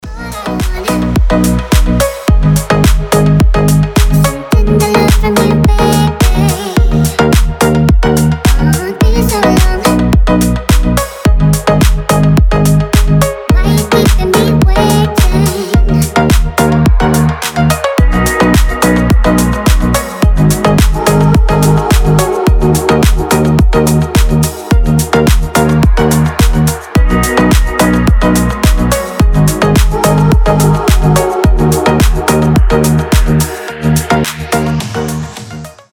• Качество: 320, Stereo
deep house
милые
мелодичные
детский голос
Красивый рингтон с забавным голосом